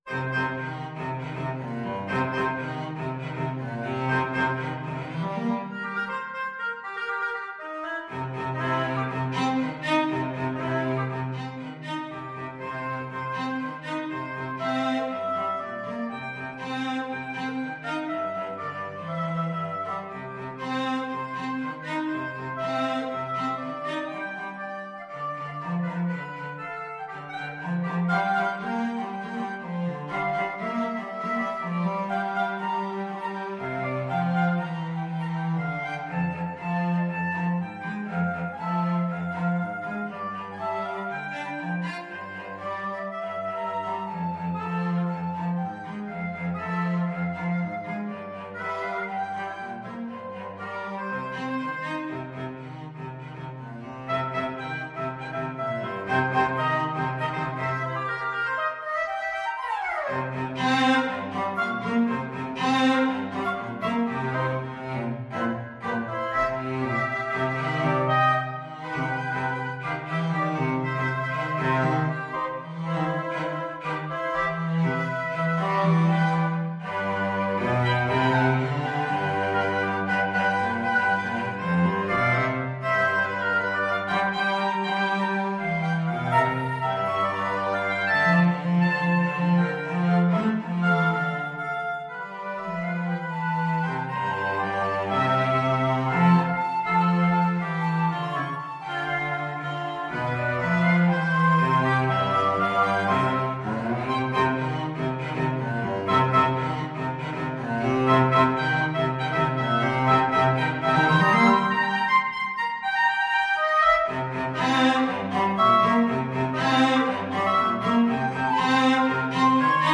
Flute
Oboe
Cello
with a funky twist…
Funky and Fast =c.120
4/4 (View more 4/4 Music)